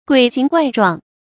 鬼形怪狀 注音： ㄍㄨㄟˇ ㄒㄧㄥˊ ㄍㄨㄞˋ ㄓㄨㄤˋ 讀音讀法： 意思解釋： 猶言奇形怪狀。